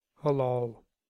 Ääntäminen
US Tuntematon aksentti: IPA : /hæ.ˈlɑːl/